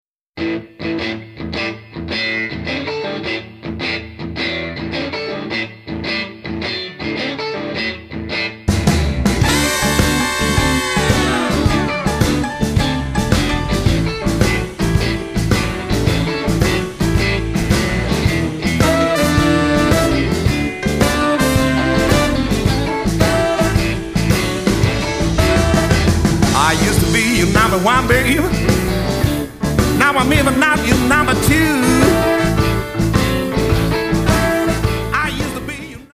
vocal, guitar
organ, piano
harp
bass
drums